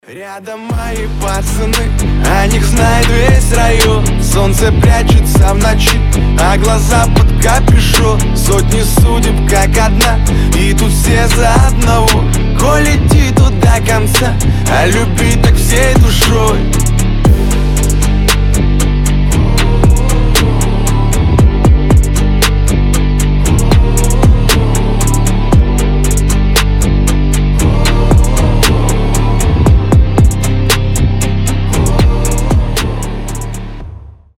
душевные
басы
Рэп-рок